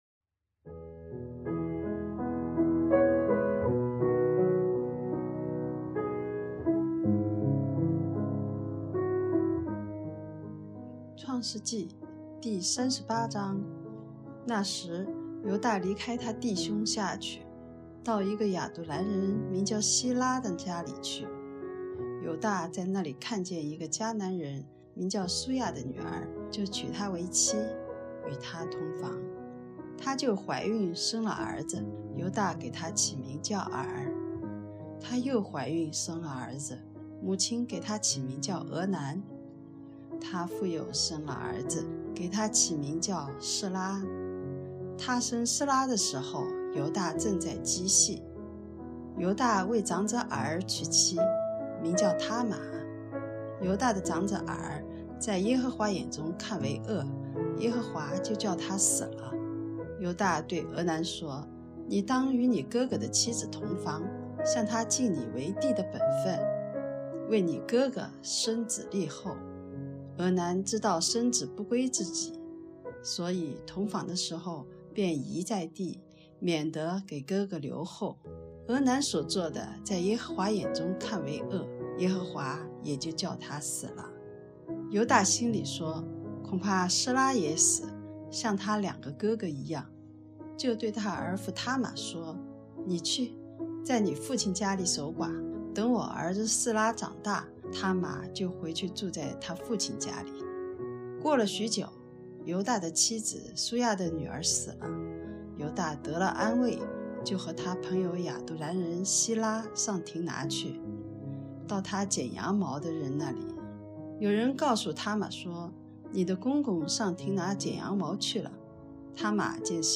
读经马拉松 | 创世记38章(国语)